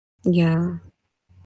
yeah-2.mp3